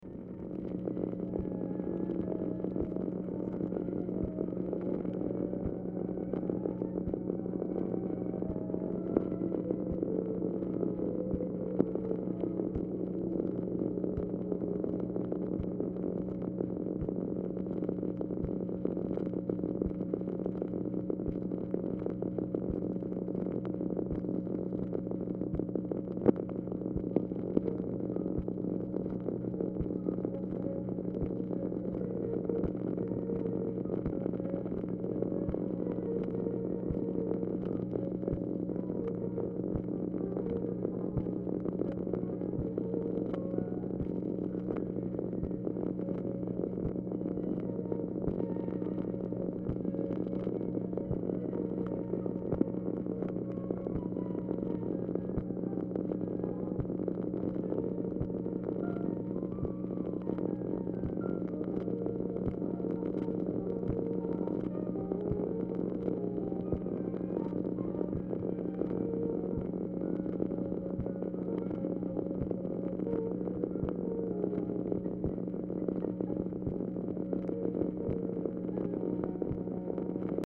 Telephone conversation # 6891, sound recording, OFFICE NOISE, 2/26/1965, time unknown | Discover LBJ
Format Dictation belt
Specific Item Type Telephone conversation